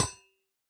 Minecraft Version Minecraft Version snapshot Latest Release | Latest Snapshot snapshot / assets / minecraft / sounds / block / copper_grate / break1.ogg Compare With Compare With Latest Release | Latest Snapshot
break1.ogg